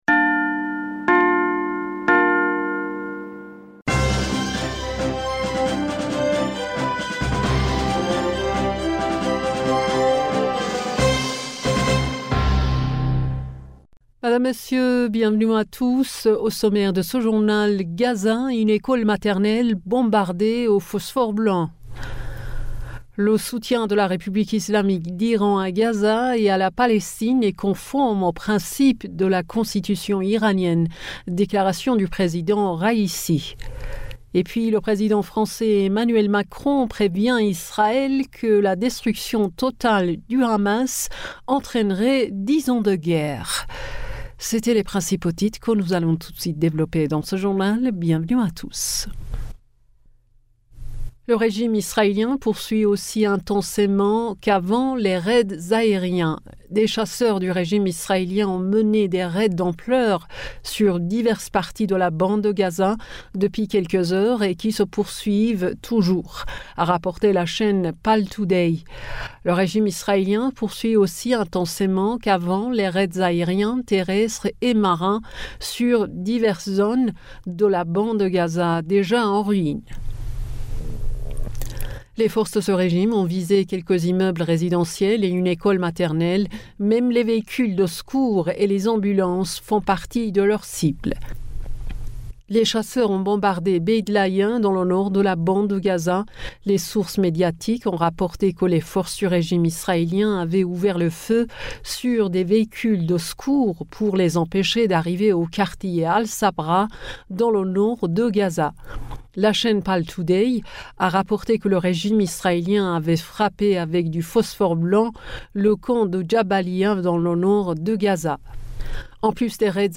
Bulletin d'information du 03 Decembre 2023